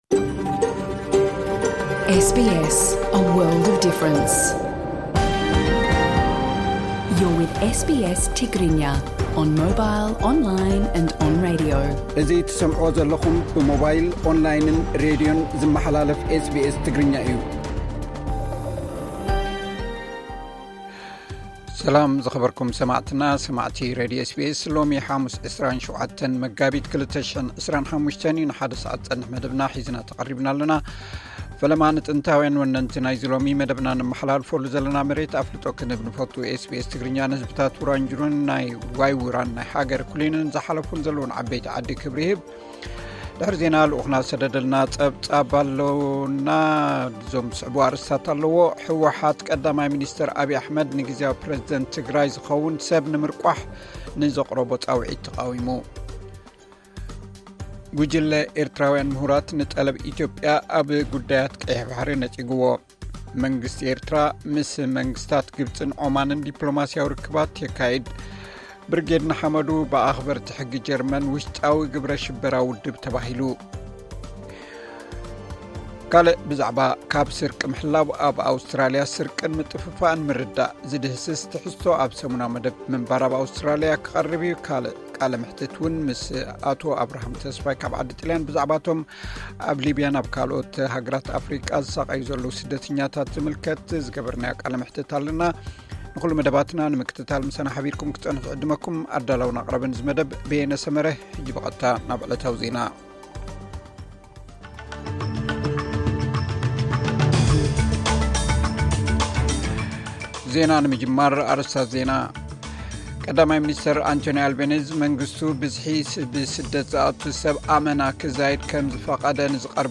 ቃለ መሕትት’ውን ኣለና።